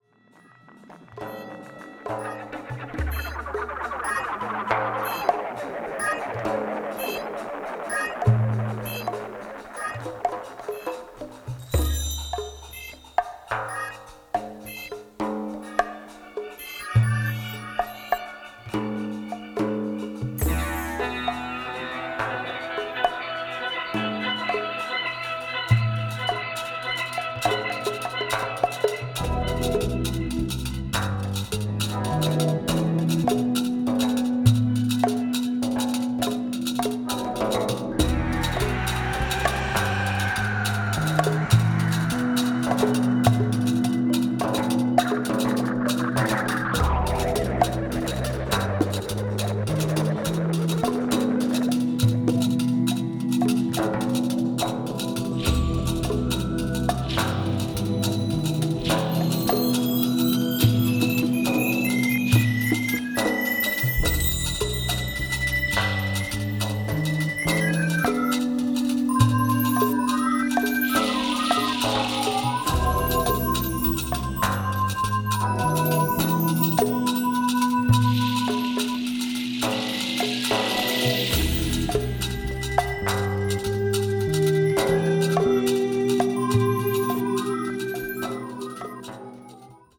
a trance like groove